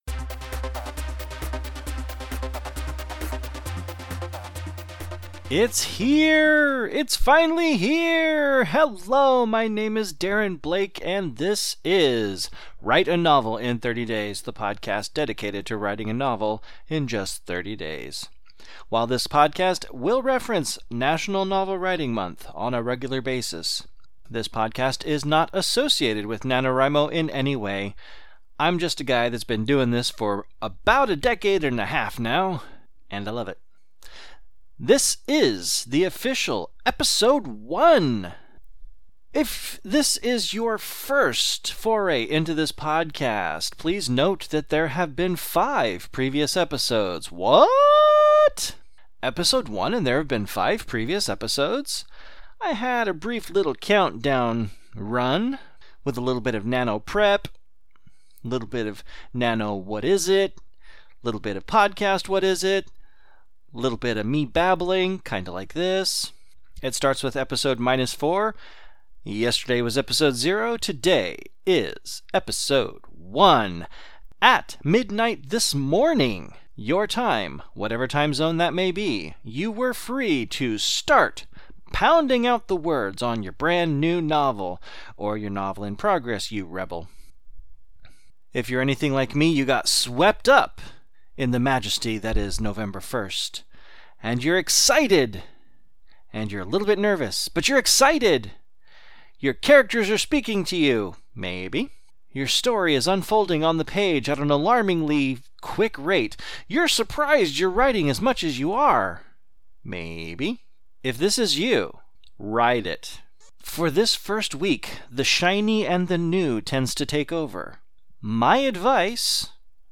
Music: “Raving Energy (faster)”